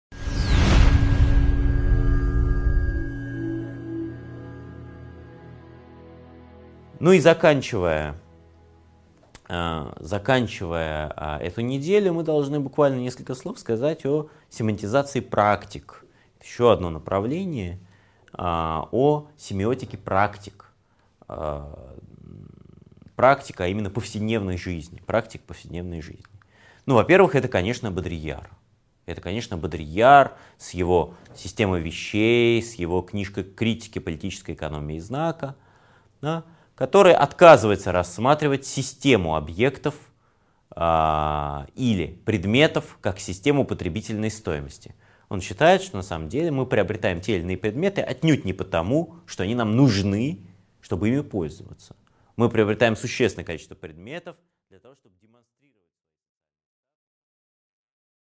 Аудиокнига 7.9 Лингвистические теории: Семантизация практик | Библиотека аудиокниг